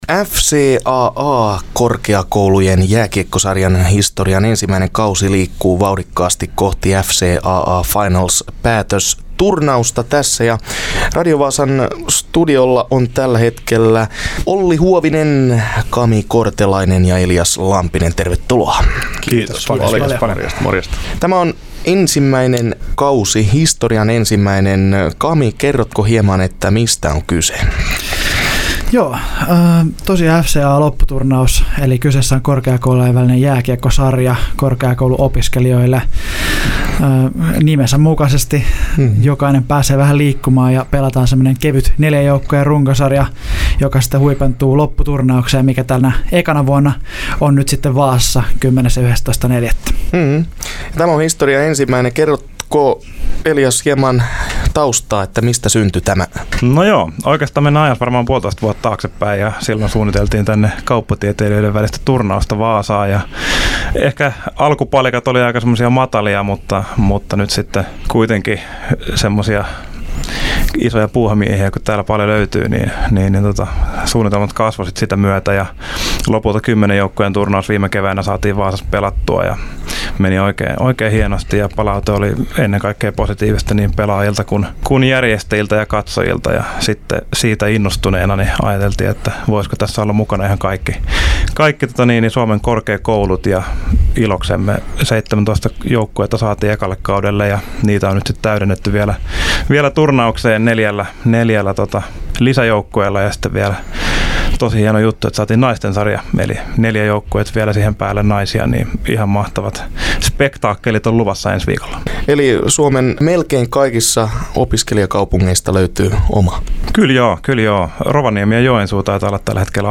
Radio Vaasan studiolla vieraili